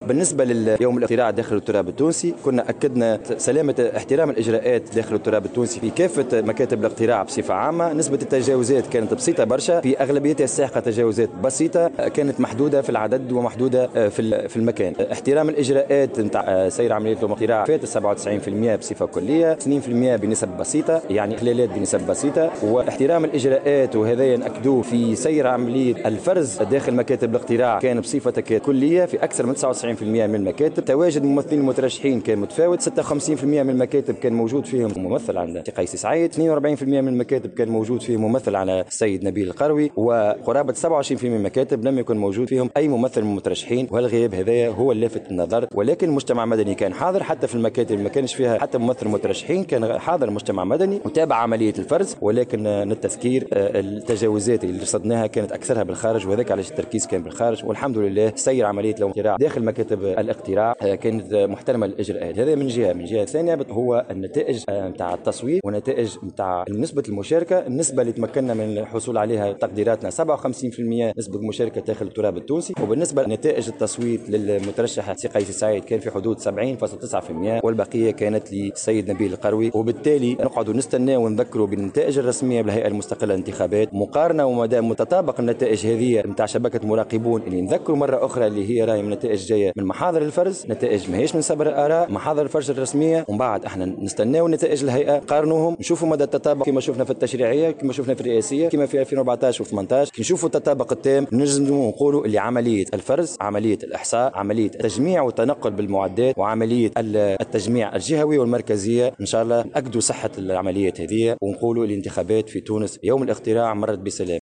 قدمت شبكة مراقبون اليوم في ندوة صحفية النتائج التقديرية للدور الثاني من الانتخابات الرئاسية التي أظهرت حصول المترشح قيس سعيد على 70،9 بالمائة فيما حصل المترشح الثاني نبيل القروي على نسبة 29،1 وذلك استنادا إلى آلية الفرز السريع للأصوات (بالاعتماد على محاضر الفرز) .